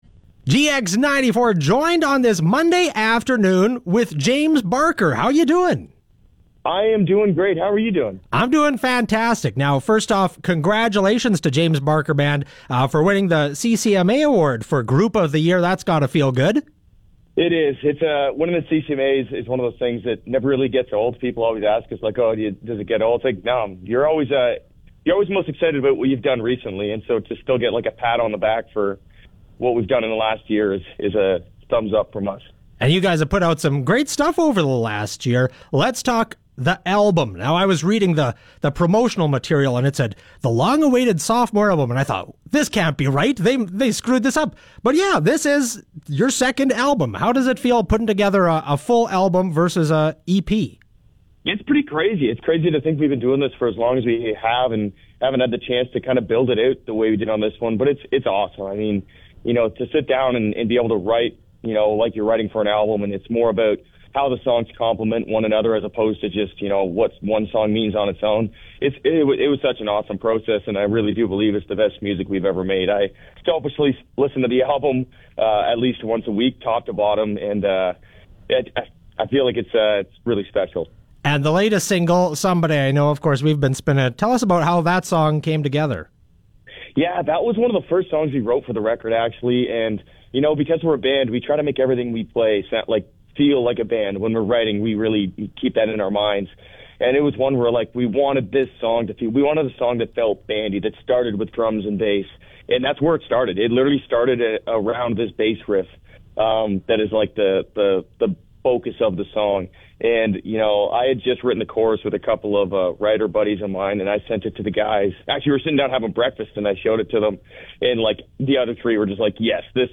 Interview with James Barker Band